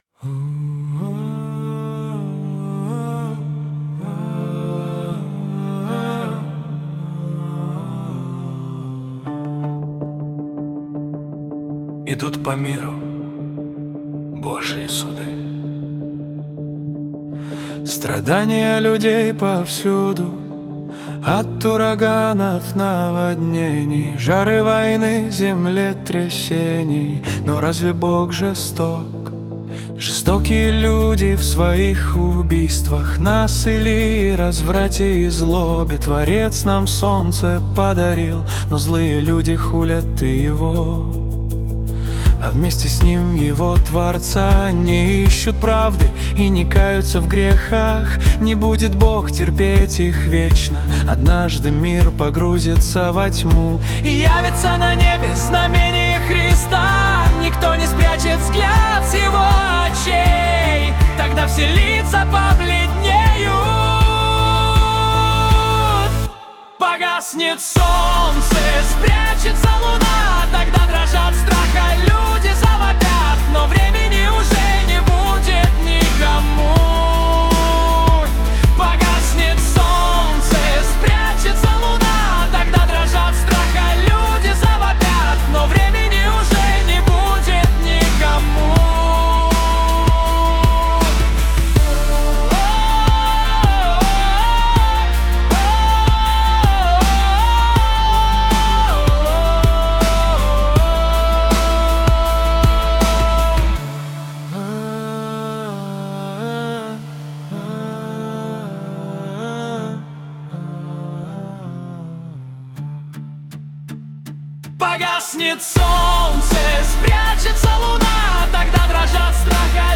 Нейросеть поёт Христу.
Представленные ниже песни были созданы с помощью нейронной сети на основе наших стихов